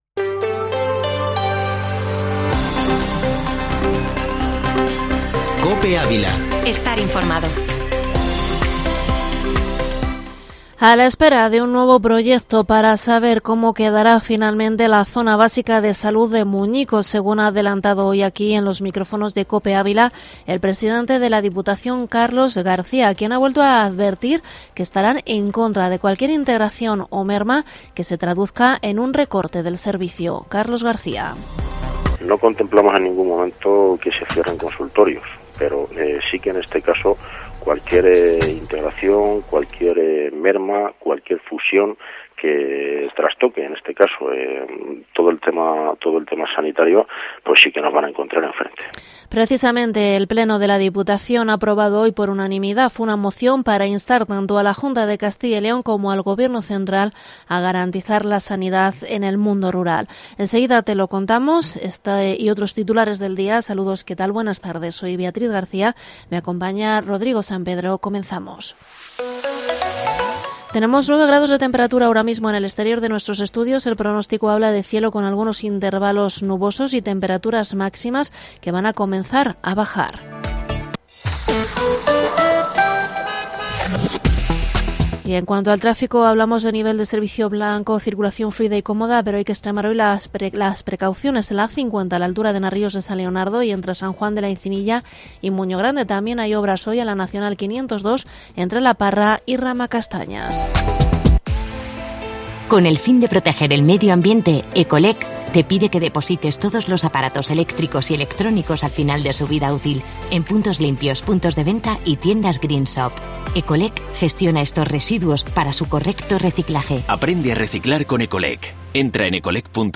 informativo Mediodía COPE ÁVILA 25/10/2021